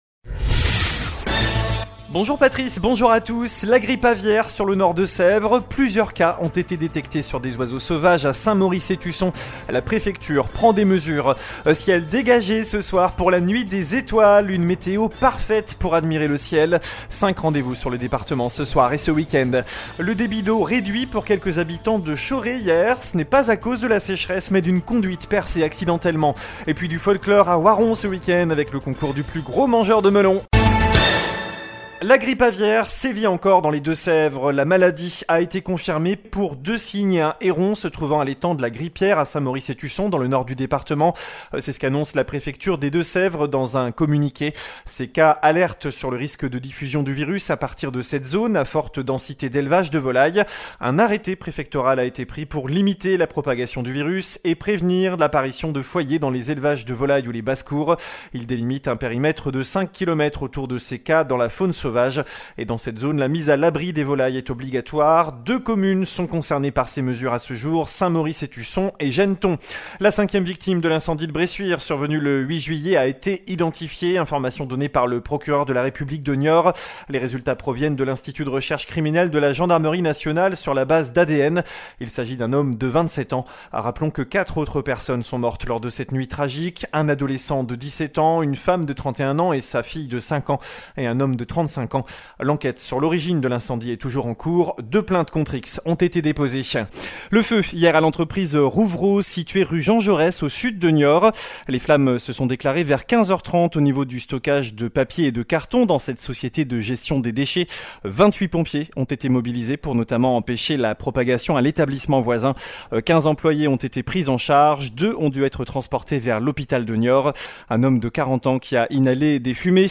JOURNAL DU VENDREDI 05 AOÛT